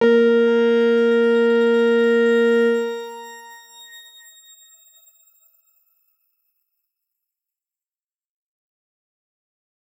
X_Grain-A#3-mf.wav